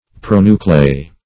Pronucleus \Pro*nu"cle*us\, n.; pl. Pronuclei (-[imac]). [NL.